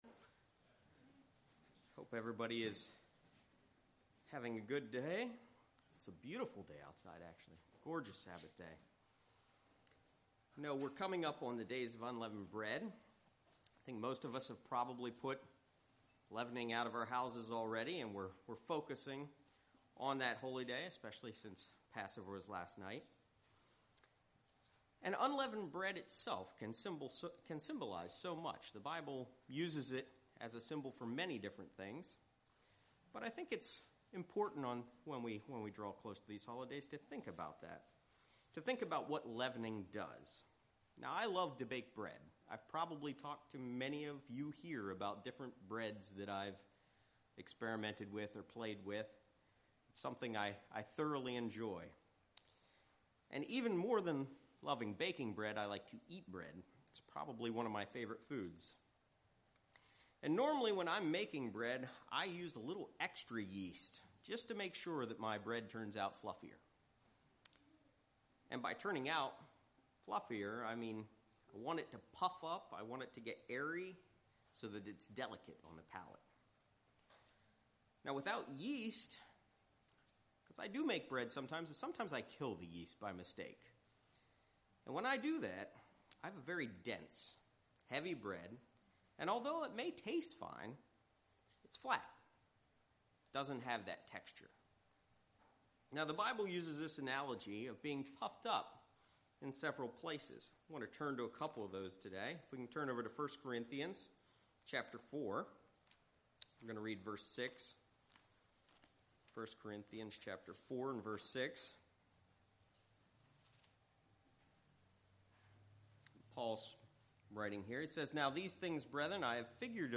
Sermons
Given in Lehigh Valley, PA